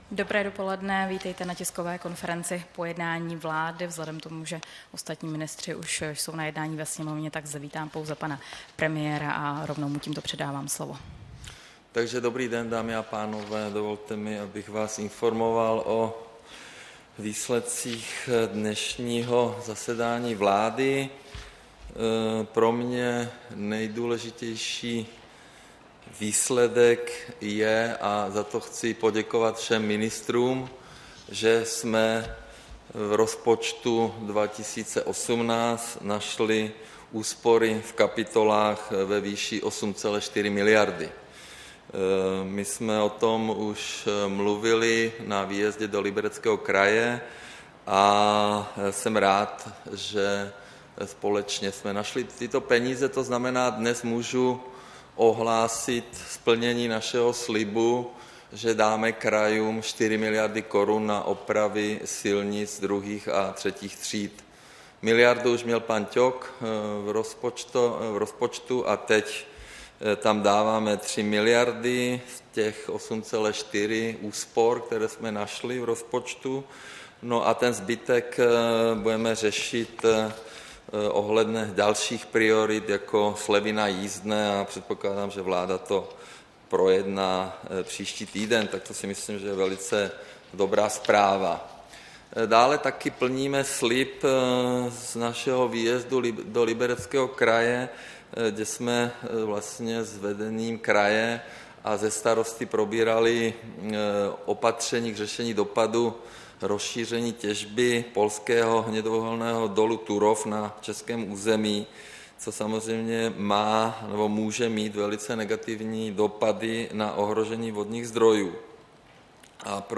Tisková konference po jednání vlády, 21. března 2018